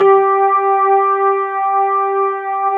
FEND1H  G3-R.wav